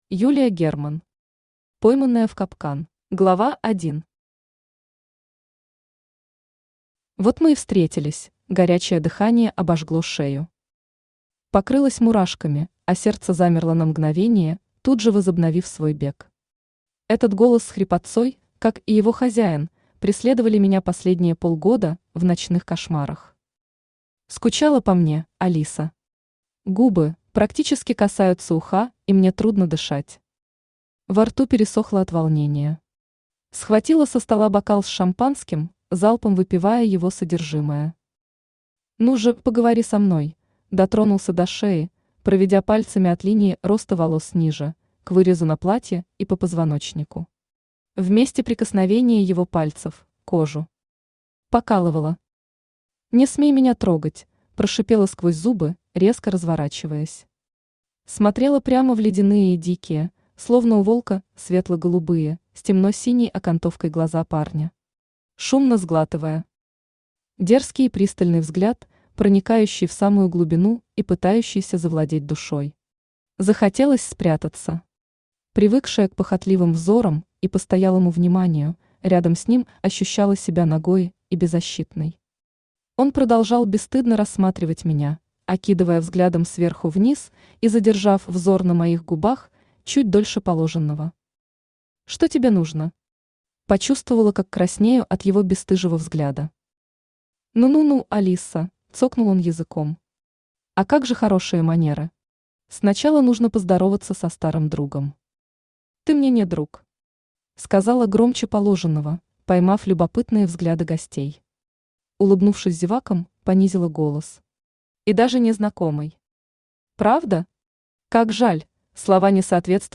Аудиокнига Пойманная в капкан | Библиотека аудиокниг
Aудиокнига Пойманная в капкан Автор Юлия Михайловна Герман Читает аудиокнигу Авточтец ЛитРес.